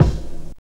LONG VINYL.wav